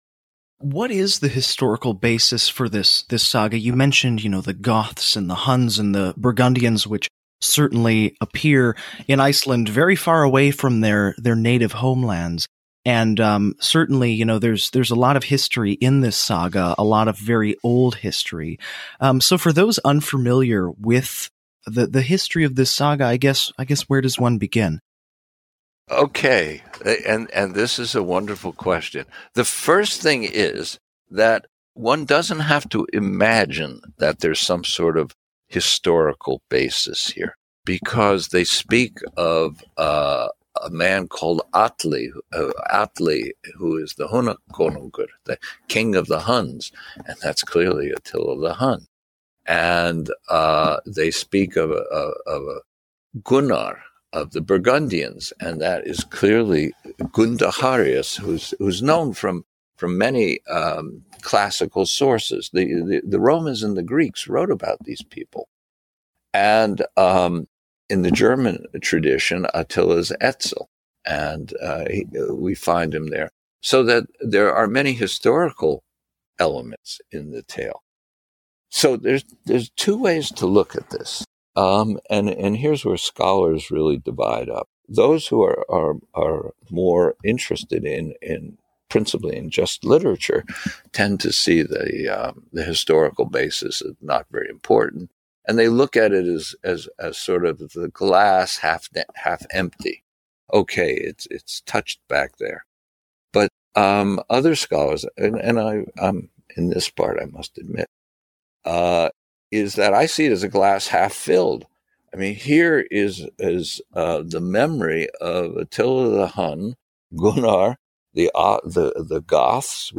We’ve collected some of the best parts of their conversation here, but you can listen to the full podcast here.